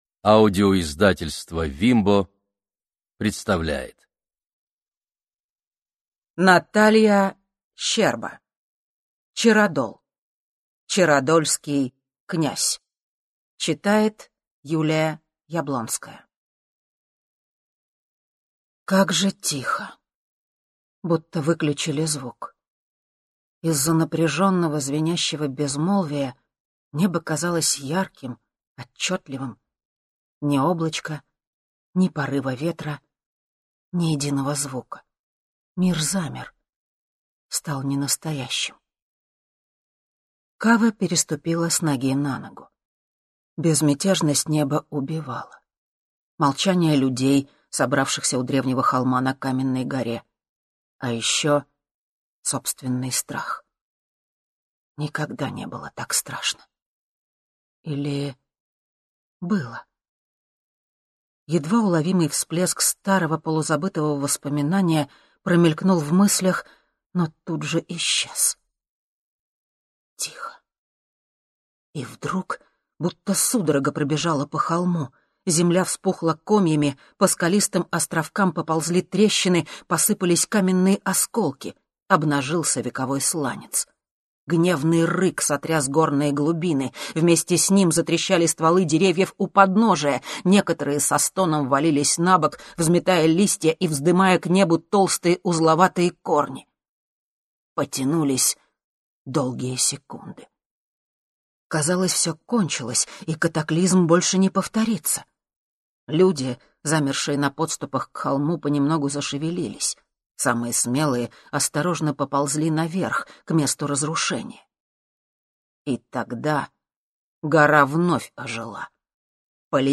Аудиокнига Чародол. Чародольский Князь | Библиотека аудиокниг